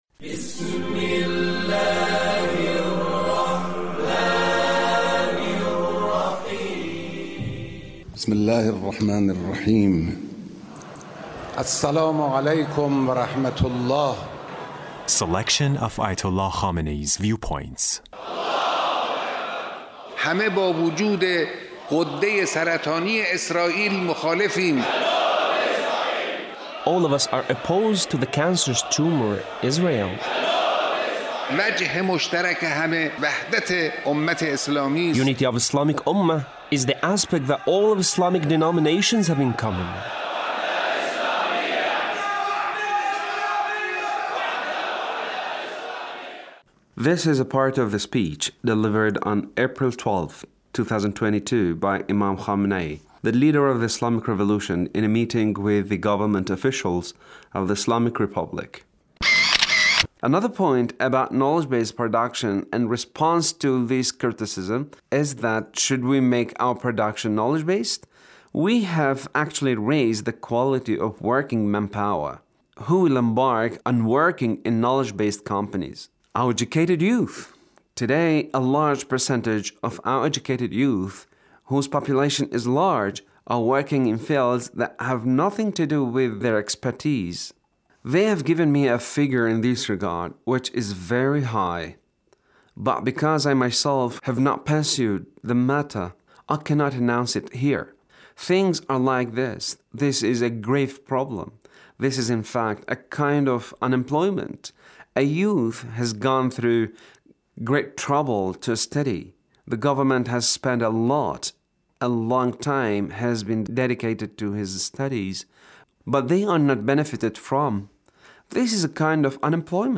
Leader's speech (1391)